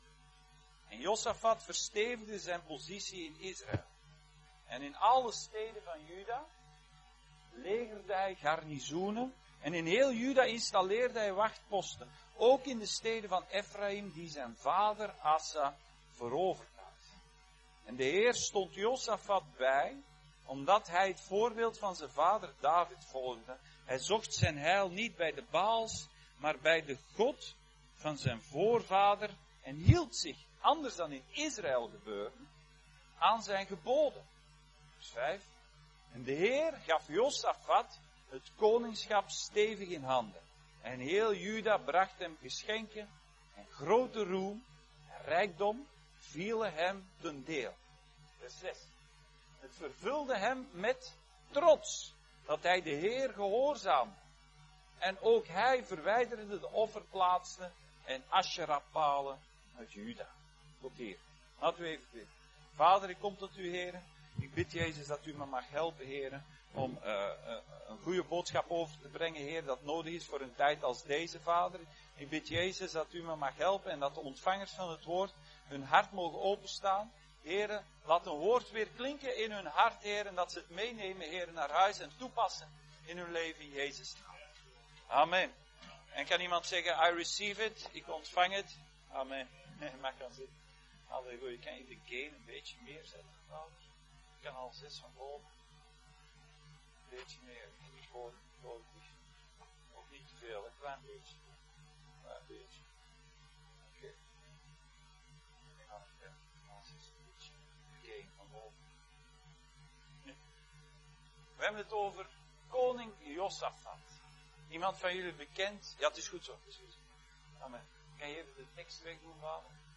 Josafat Dienstsoort: Zondag Dienst « Boek van Hebreeën